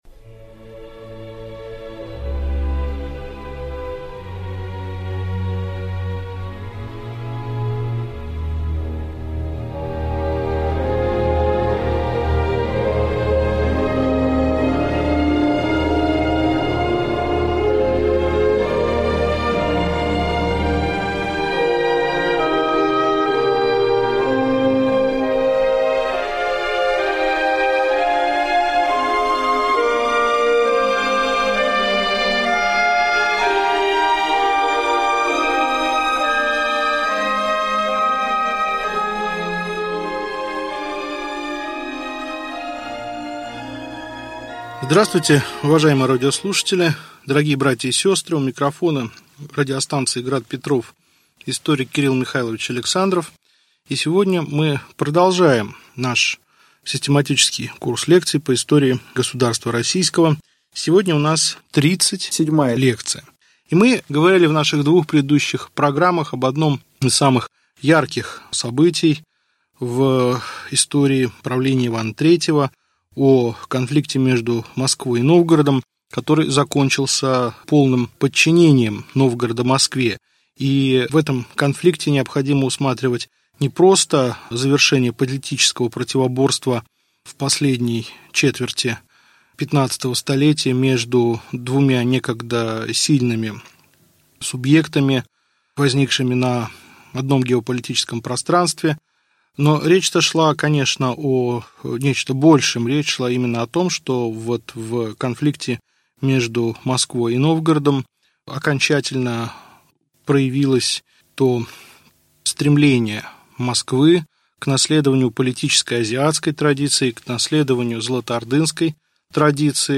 Аудиокнига Лекция 37. Конец татаро-монгольского ига | Библиотека аудиокниг